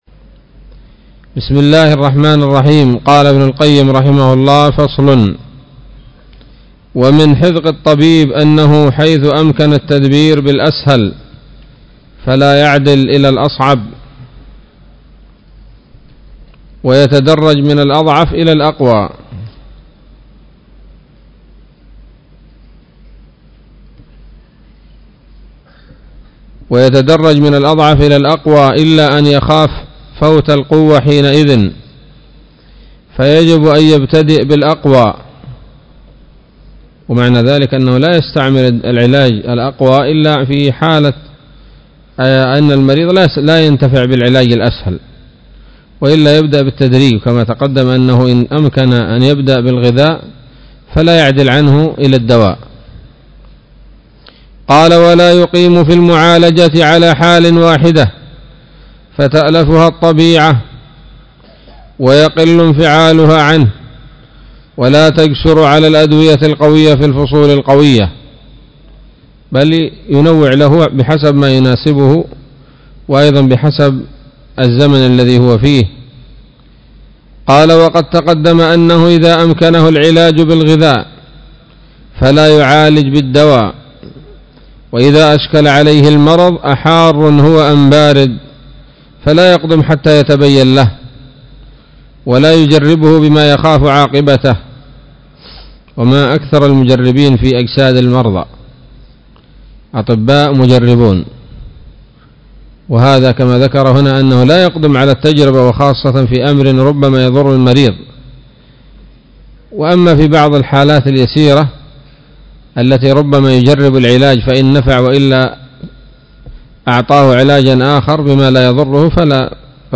الدرس الأربعون من كتاب الطب النبوي لابن القيم